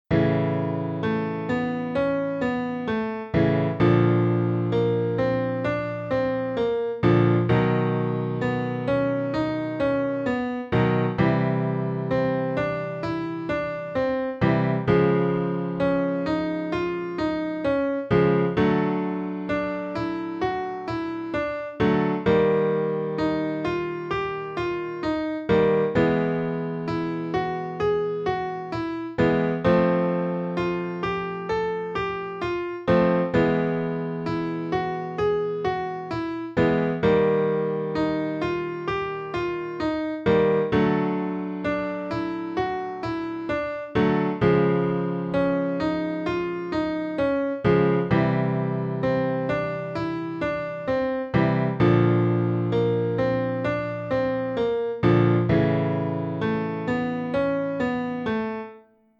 ćwiczenie „Brr” od dźwięku A2 do E3 powrót do A2
ćwiczenie Brr
04-Brr-My-Na-A2-E3.mp3